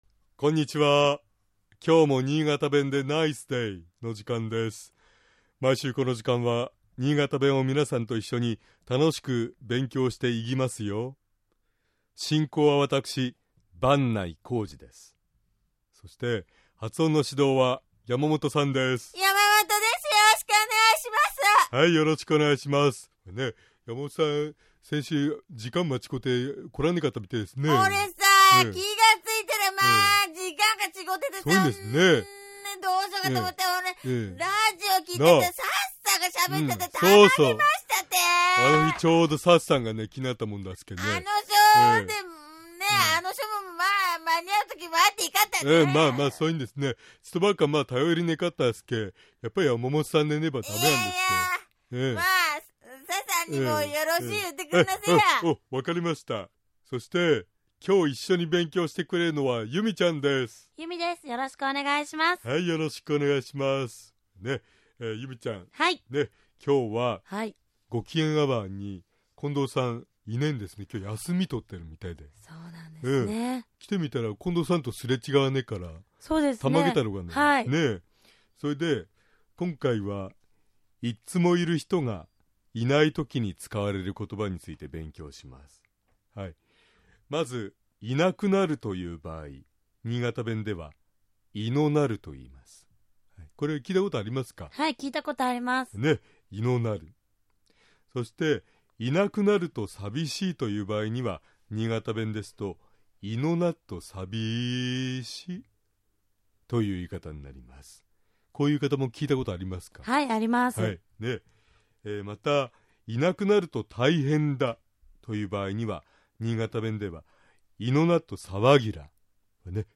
まず、「いなくなる」と言う場合、新潟弁では「いのなる」と発音します。